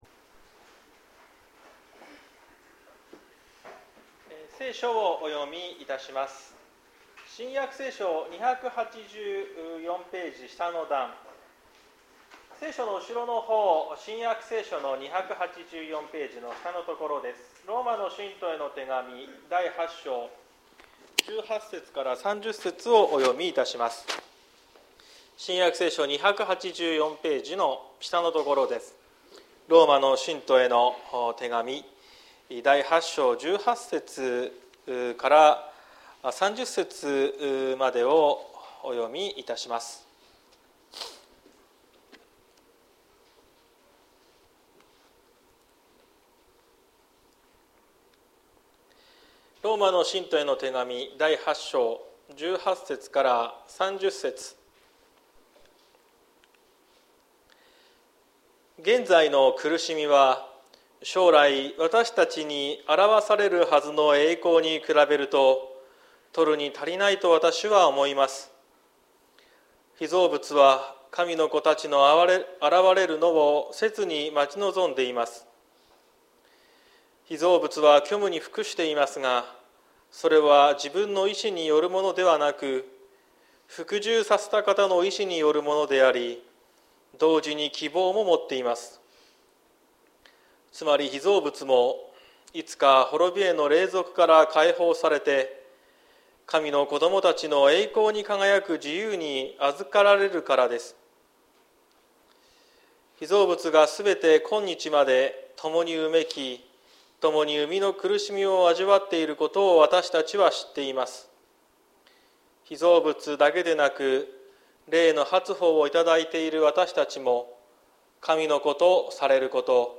2024年04月28日朝の礼拝「全てが益となる」綱島教会
説教アーカイブ。